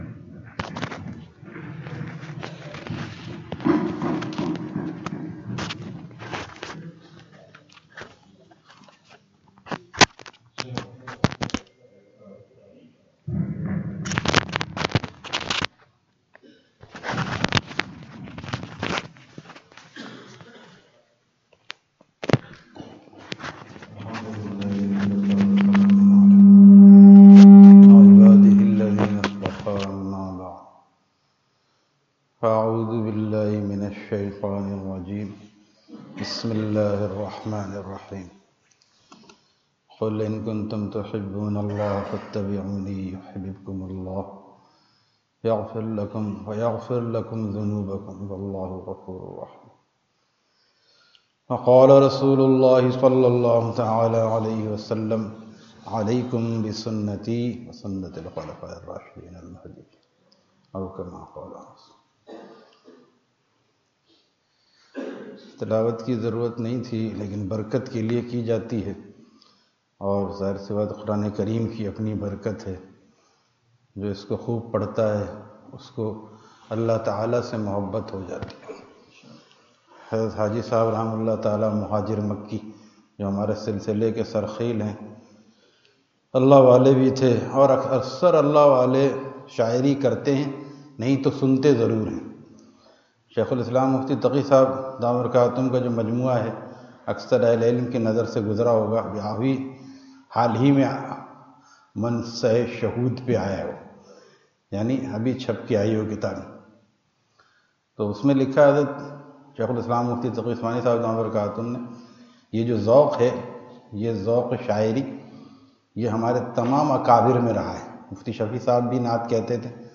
Bayan at Miftah ul Uloom Madarsa, Site Area, Hyderabad